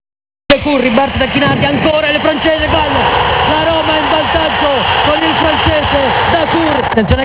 Roma, Stadio Olimpico,
Il gol di Dacourt scaricato dalla RAI
boatoromajuve4-0goaldacourt.WAV